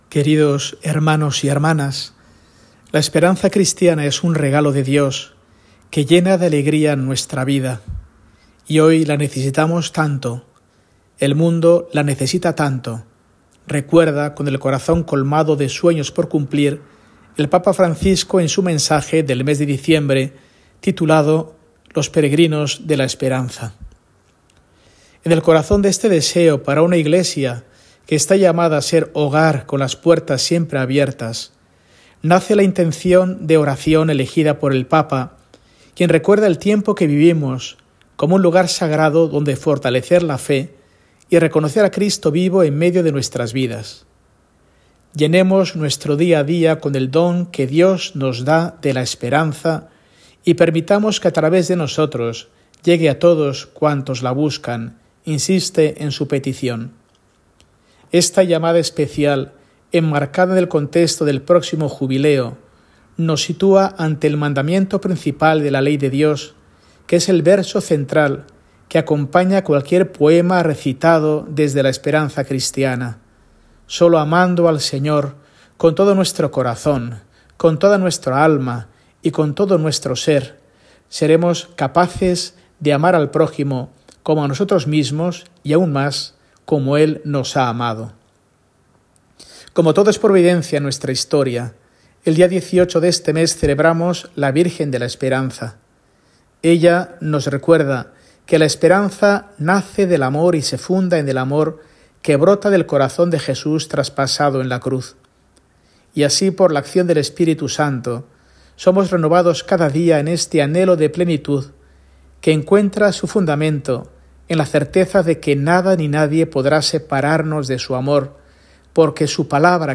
Mensaje semanal de Mons. Mario Iceta Gavicagogeascoa, arzobispo de Burgos, para el domingo, 15 de diciembre de 2024, III Domingo de Adviento